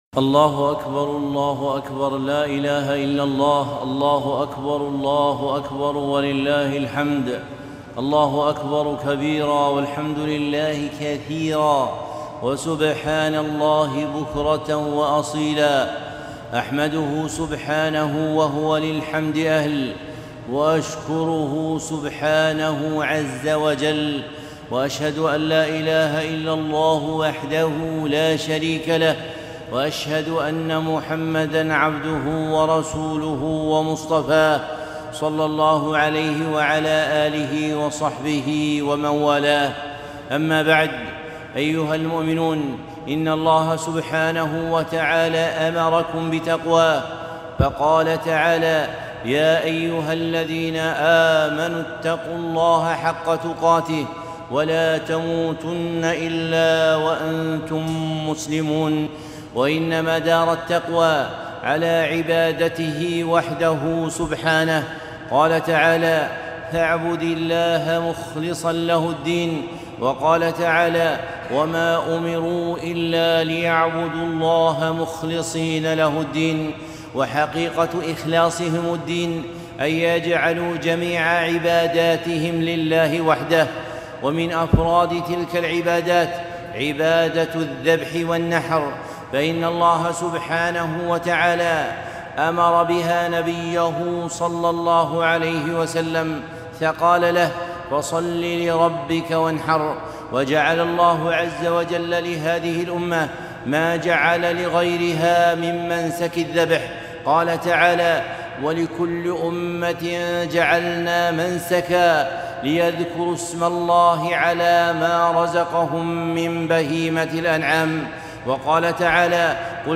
خطبة عيد الأضحى ١٤٤٢ - دروس الكويت